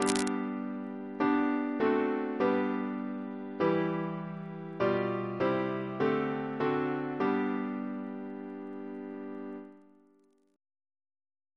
Single chant in G Composer: James Turle (1802-1882), Organist of Westminster Abbey Reference psalters: ACB: 284